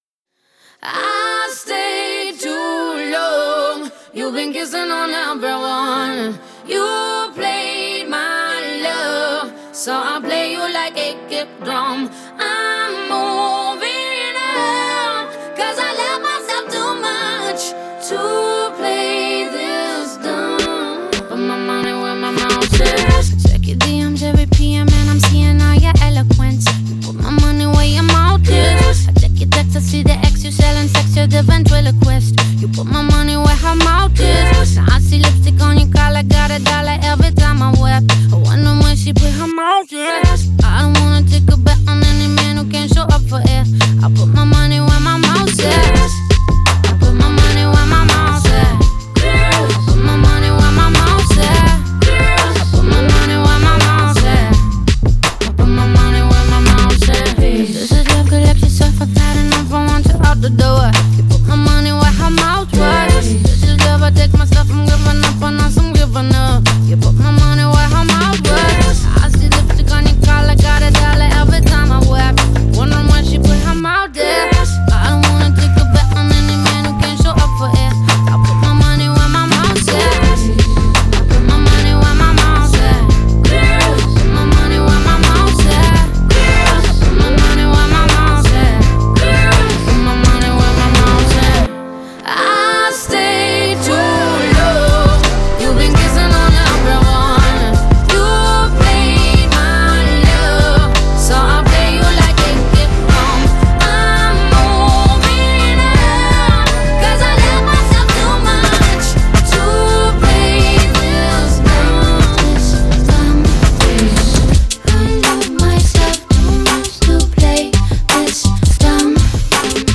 Песня выделяется ярким, запоминающимся мелодическим хором